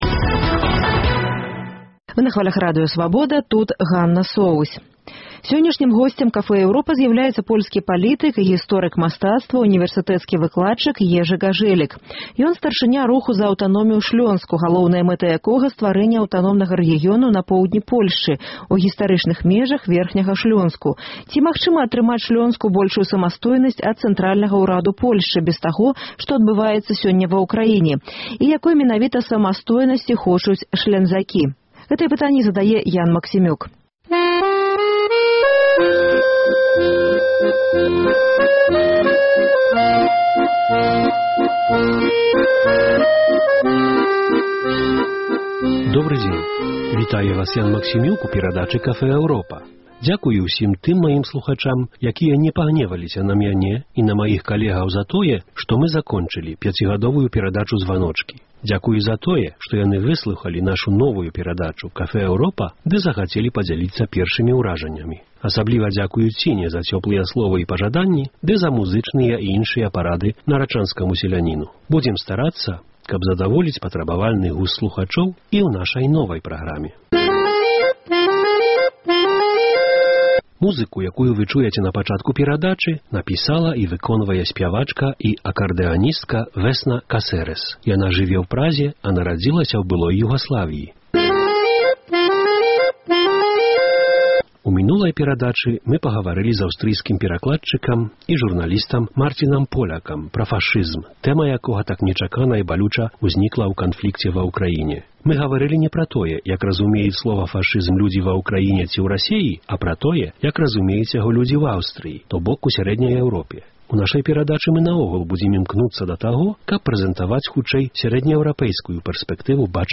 Госьць Café Europa – польскі палітык, гісторык мастацтва, унівэрсытэцкі выкладчык Ежы Гажэлік.